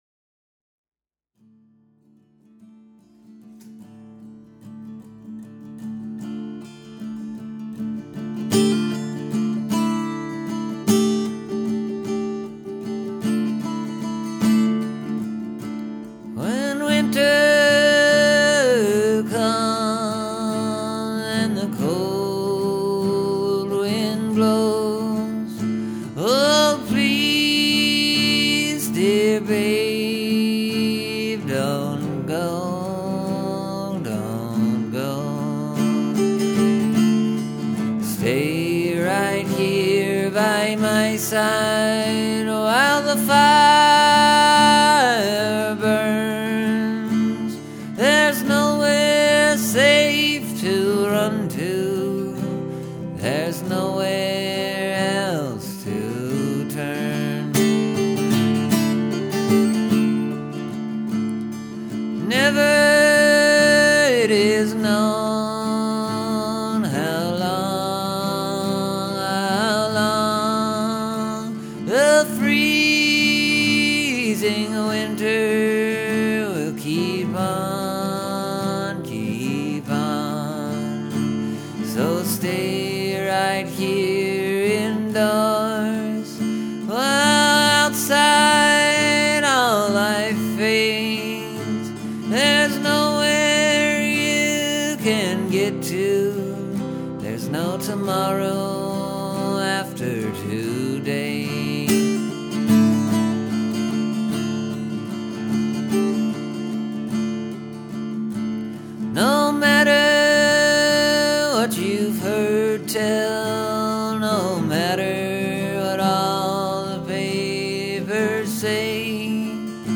First off, it’s in a minor key.